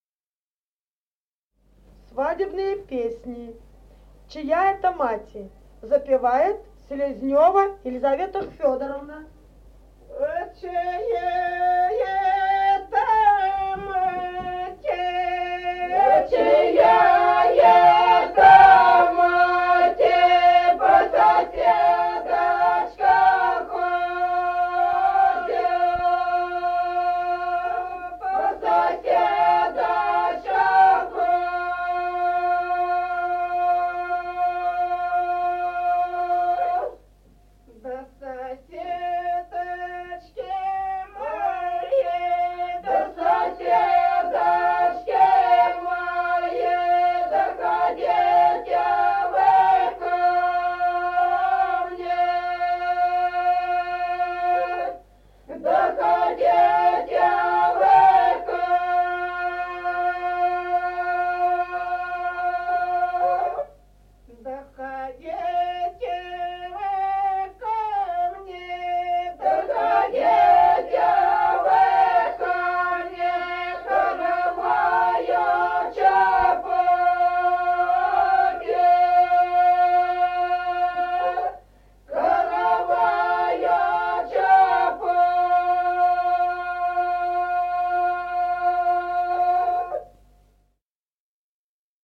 Народные песни Стародубского района «А чия это мати», свадебная.
1953 г., с. Остроглядово.